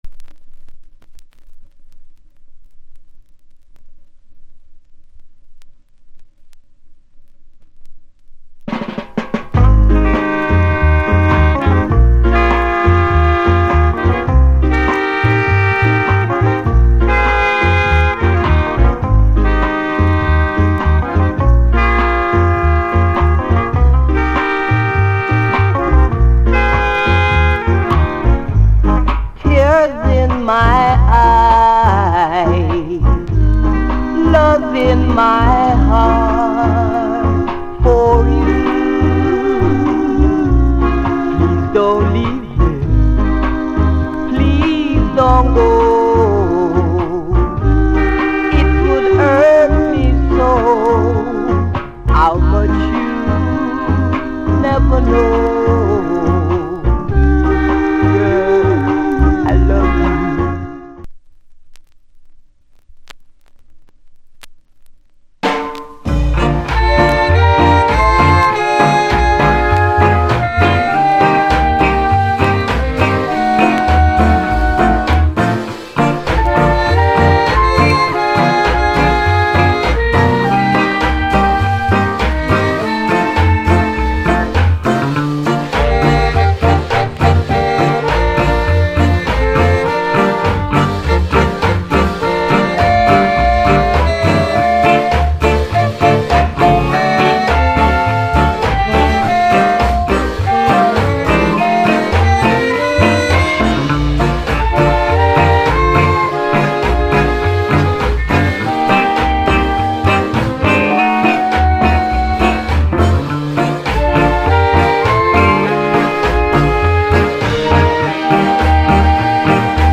Genre Rock Steady / [A] Male Vocal [B] Inst
* 哀愁漂う甘く切ないロックステディ・ヴォーカルで、なんと未発表曲。裏面は爽やかなロックステディ・インスト。